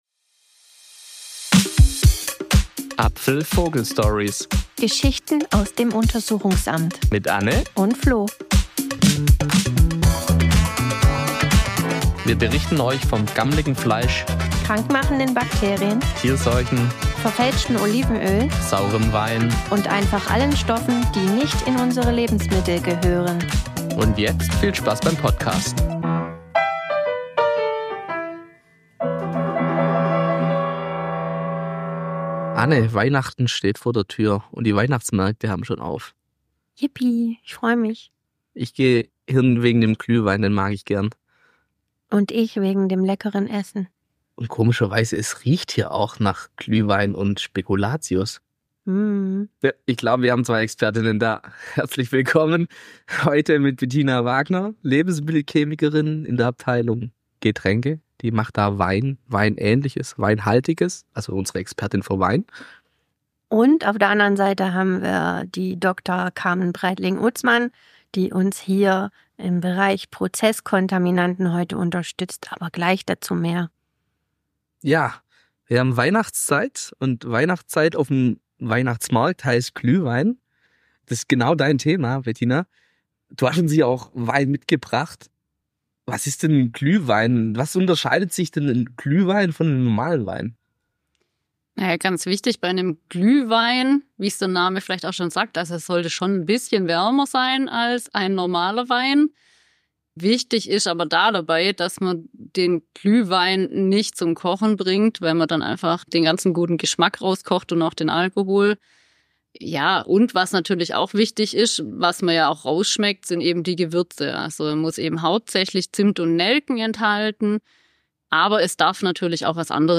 In unserer festlichen Folge dreht sich alles um Glühwein und Weihnachtsgebäck: Wir sprechen mit zwei Expertinnen, die uns aktuelle Einblicke in die Proben auf ihrem Untersuchungstisch geben.